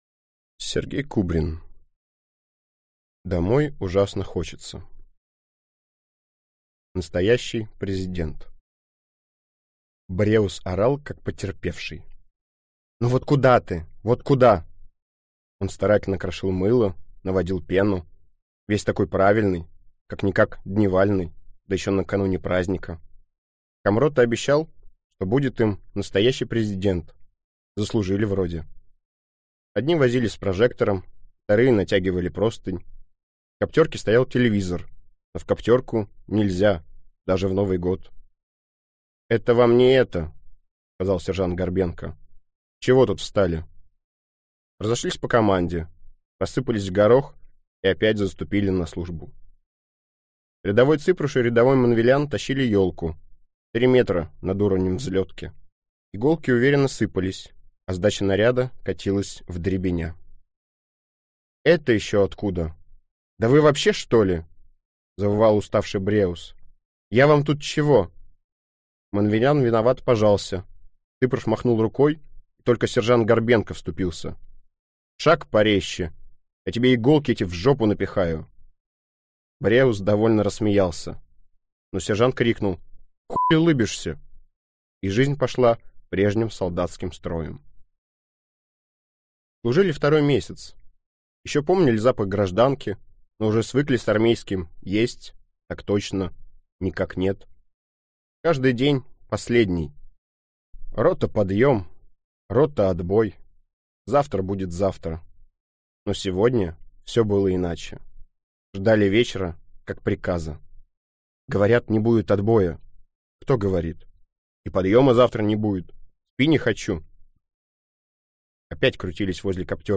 Аудиокнига Домой ужасно хочется | Библиотека аудиокниг